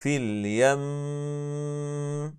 VKTIGT: När reciteraren uttalar stressad nūn eller mīm, måste han/hon förlänga ghunnah mest fullständigt(1) (2) (غنةَ أكملَ ماَتكون), det vill säga när reciteraren både fortsätter och stannar.
Exempel på när man stannar: